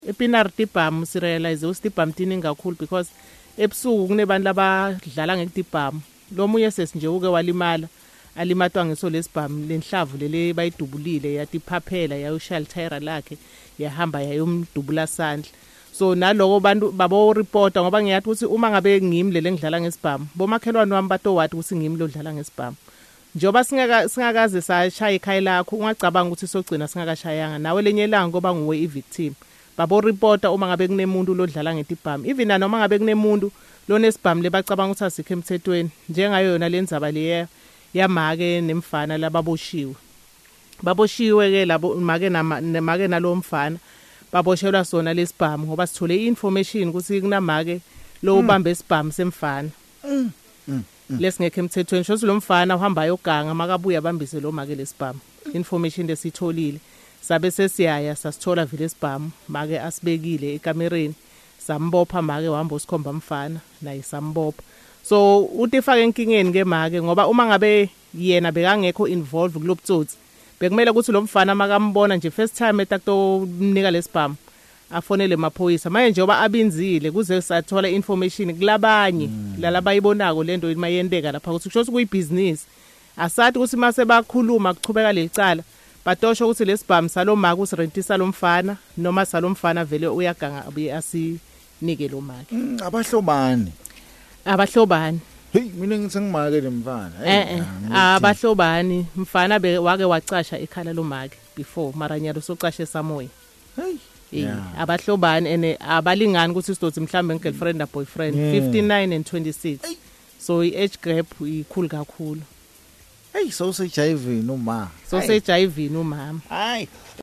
Speaking during a provincial breakfast show